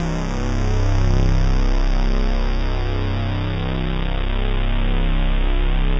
Index of /90_sSampleCDs/Trance_Explosion_Vol1/Instrument Multi-samples/Angry Trance Pad
C2_angry_trance_pad.wav